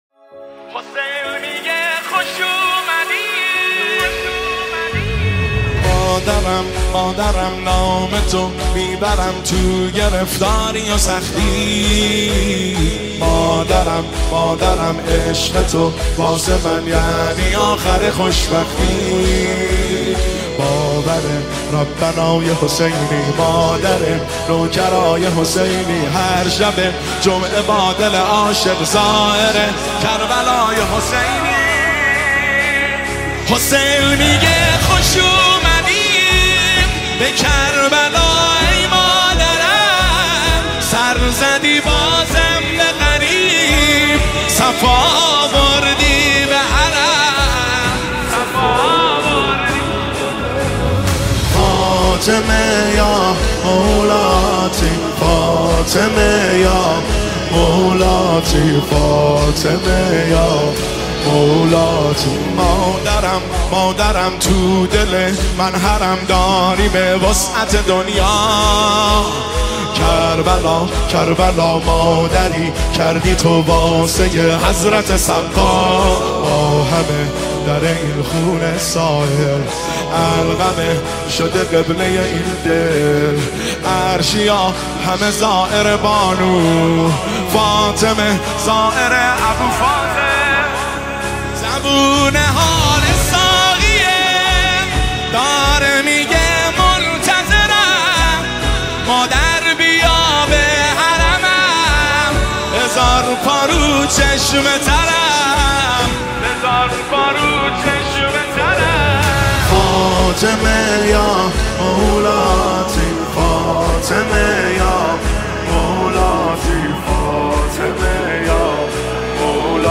نماهنگ مذهبی مداحی مذهبی